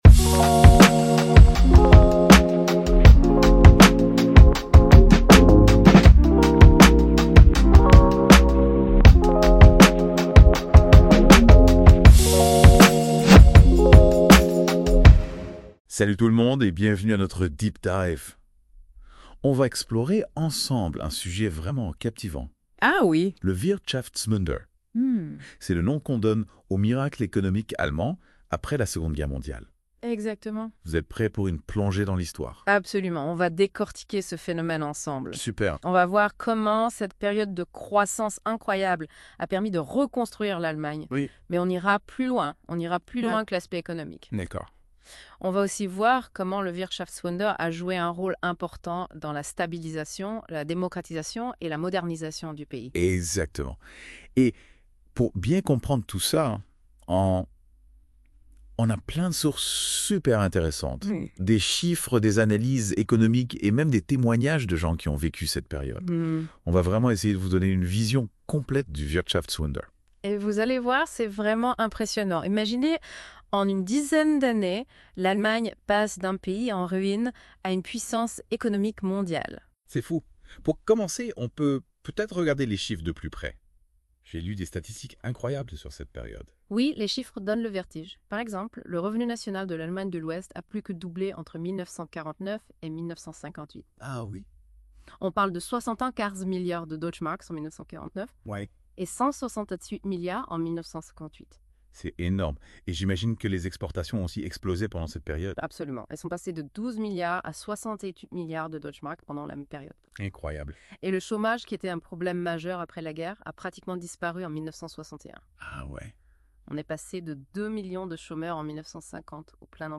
Notebook LM (IA)